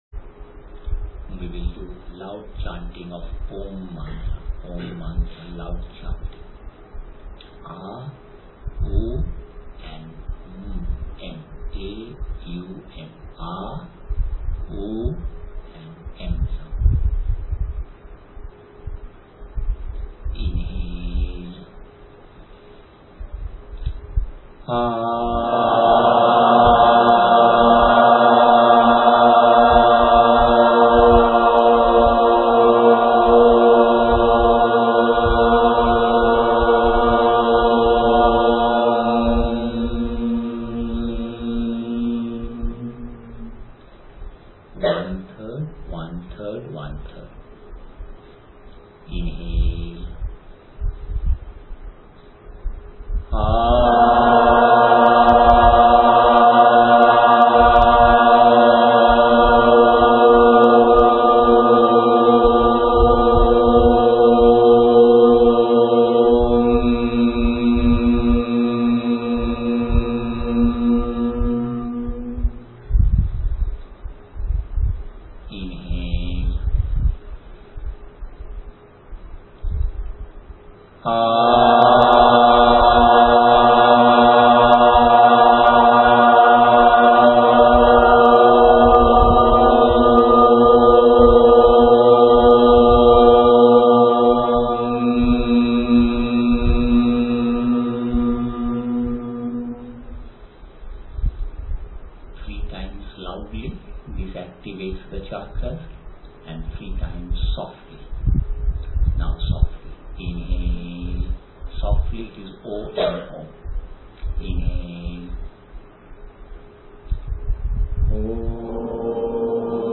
リシュケシュ レクチャー前に皆で唱えるマントラ